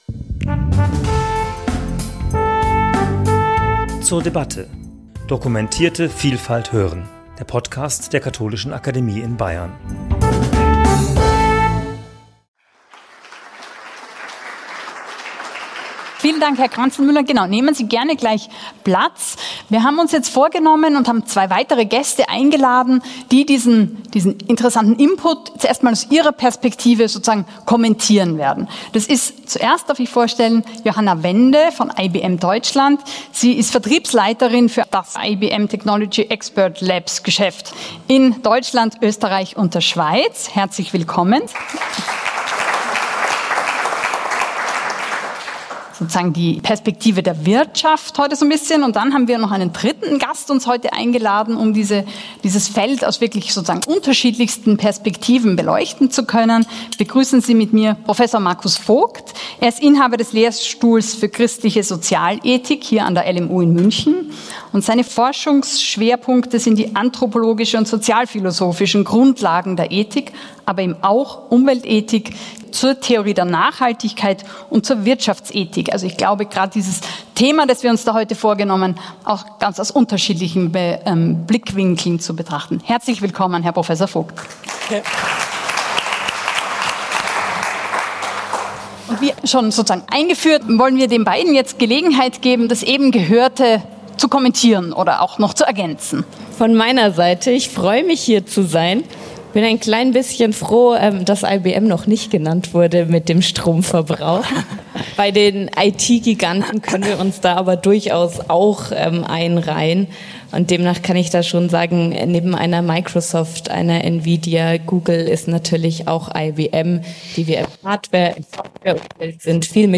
Gespräch zum Thema 'Der ökologische Fußabdruck der Digitalisierung' ~ zur debatte Podcast
Das Gespräch fand am 26.11.2024 in der Katholischen Akademie in Bayern statt.